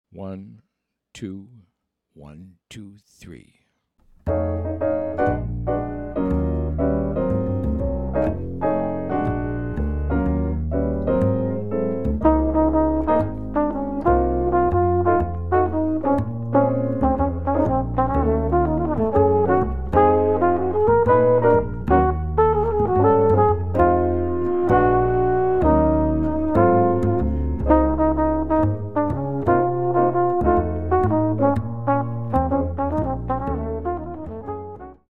flugelhorn solo 2 choruses